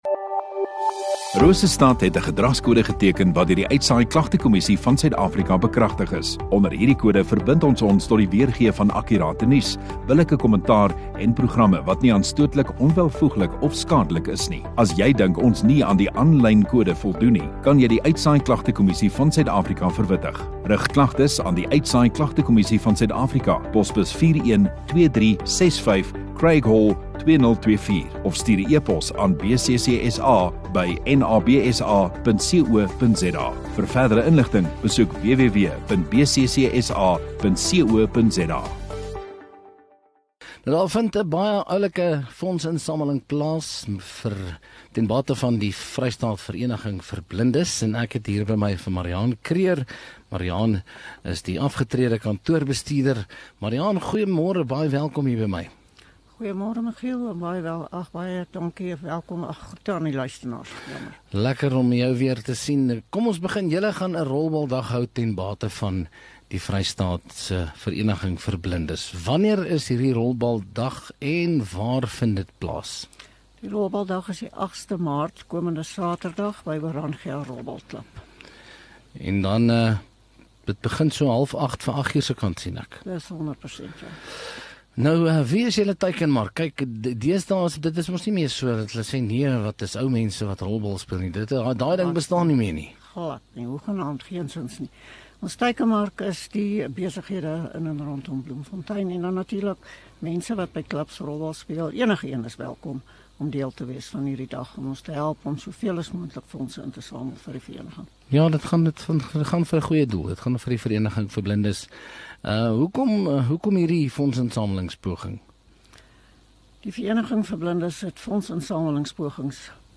Radio Rosestad View Promo Continue Radio Rosestad Install Gemeenskap Onderhoude 4 Mar Vereniging vir Blindes